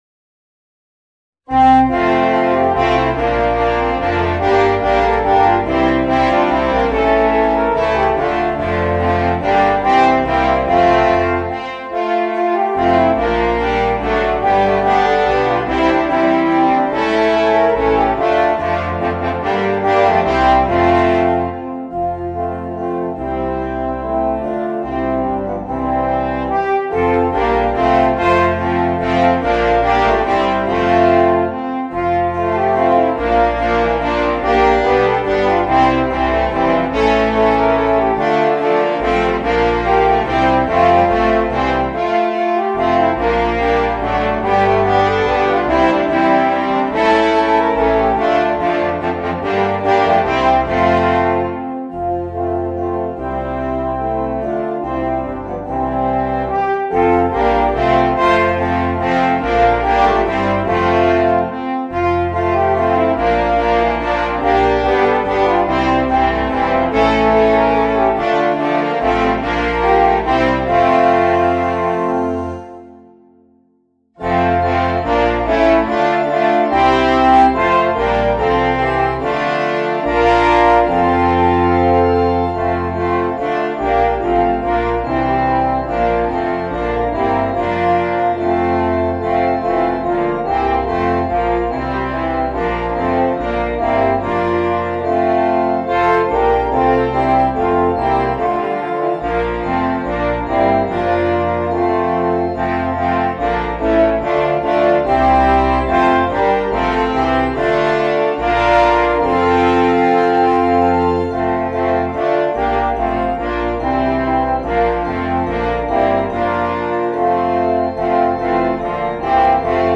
Gattung: Weihnachtslieder für fünf Hörner
Besetzung: Instrumentalnoten für Horn